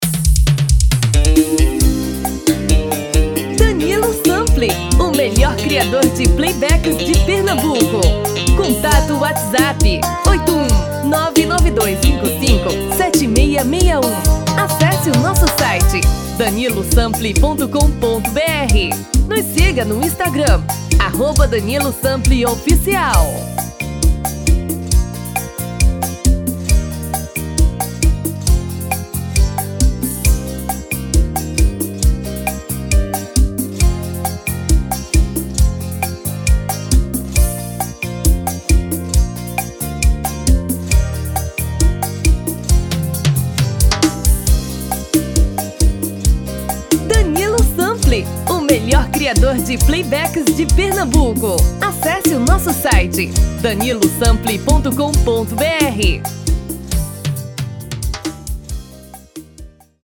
TIPO: Pot-Pourri de 4 músicas sequenciadas
RITMO: Arrocha / Seresta
TOM: Feminino (Original)